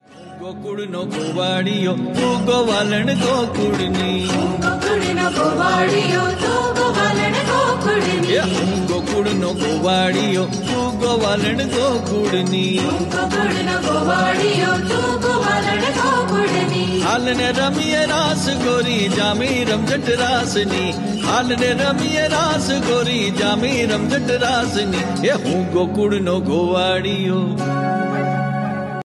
Gujarati Ringtones